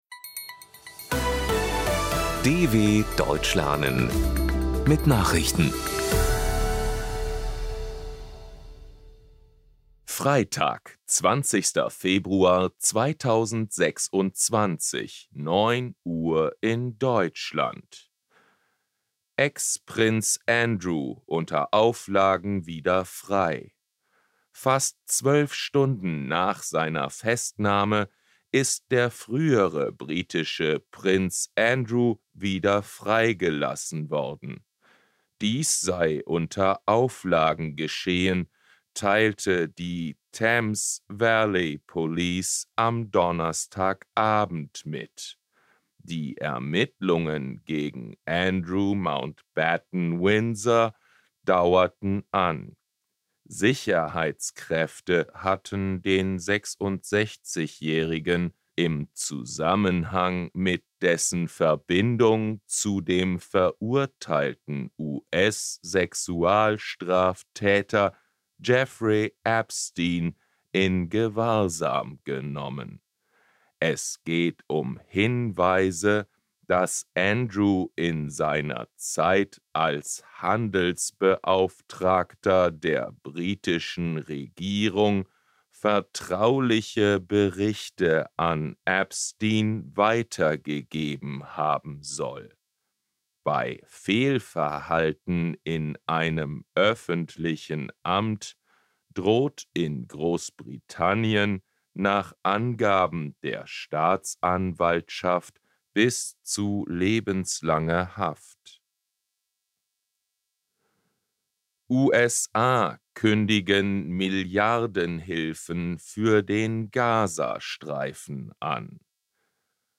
20.02.2026 – Langsam Gesprochene Nachrichten
Trainiere dein Hörverstehen mit den Nachrichten der DW von Freitag – als Text und als verständlich gesprochene Audio-Datei.